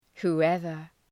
Προφορά
{hu:’evər}